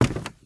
High Quality Footsteps / Wood / Wood, Creaky
STEPS Wood, Creaky, Walk 24.wav